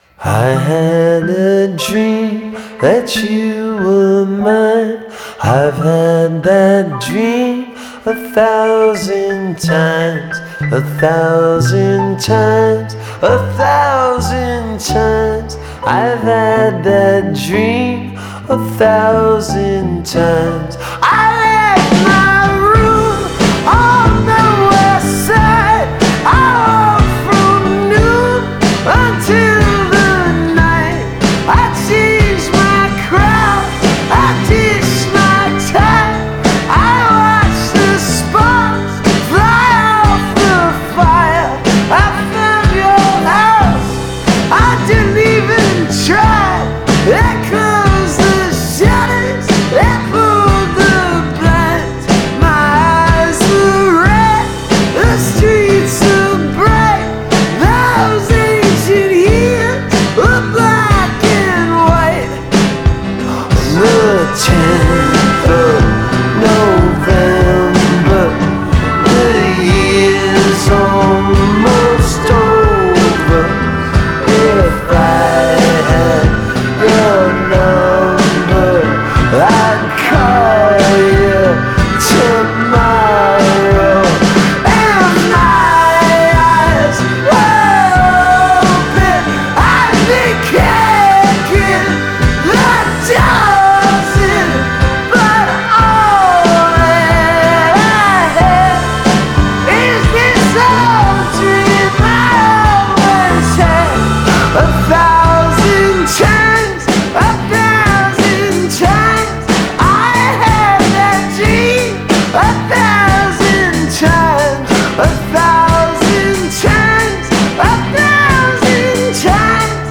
art rock